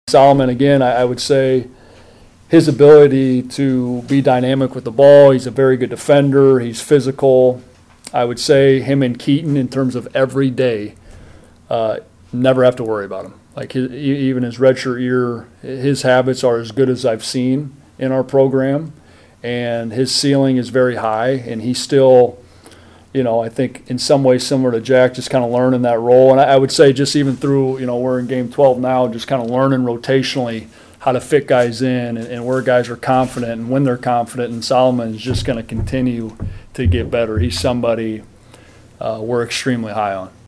POSTGAME PRESS CONFERENCE EXCERPTS